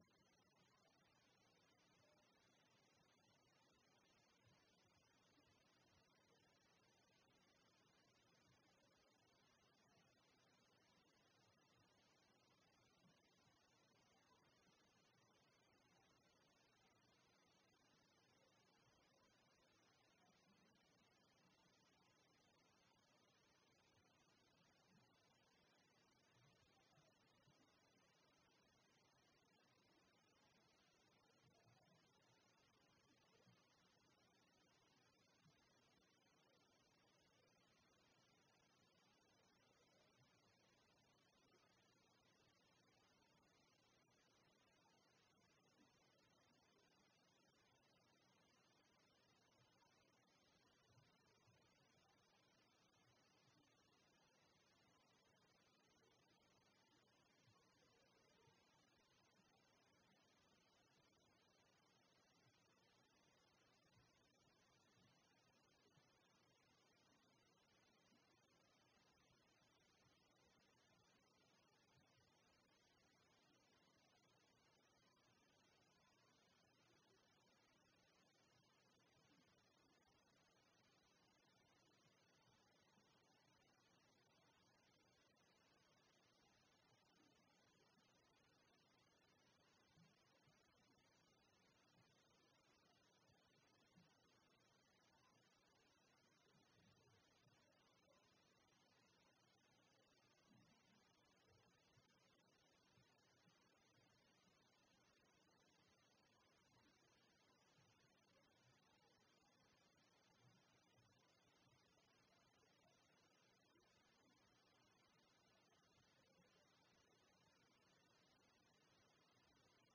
6ª Reunião Extraordinária 08-05-20.mp3